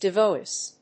devoice.mp3